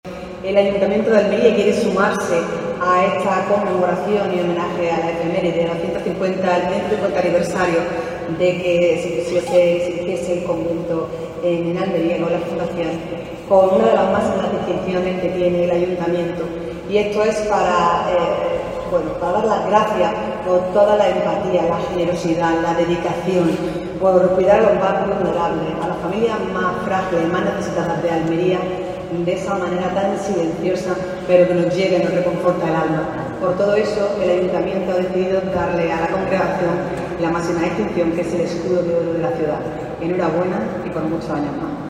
Así lo ha anunciado la alcaldesa, María del Mar Vázquez, durante la misa estacional celebrada este viernes en la Catedral de la Encarnación
ALCALDESA-ESCUDO-ORO-SIERVAS-DE-MARIA.mp3